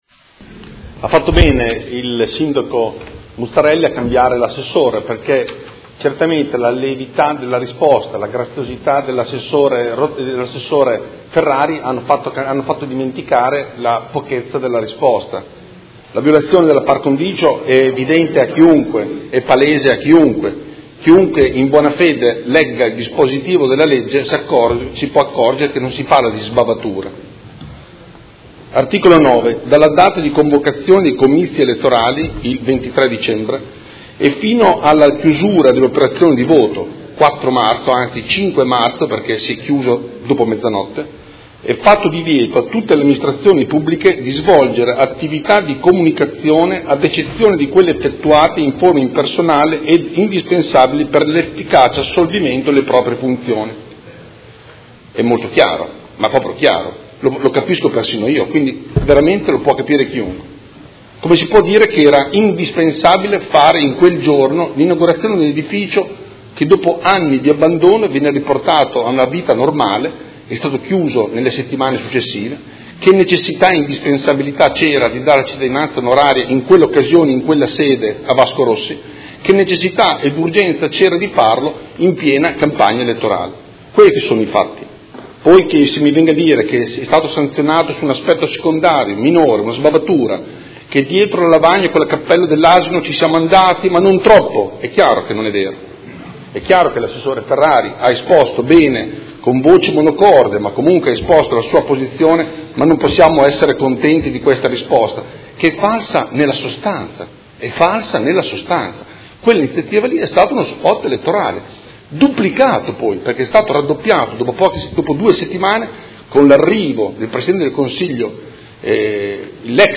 Andrea Galli — Sito Audio Consiglio Comunale
Seduta del 19/04/2018.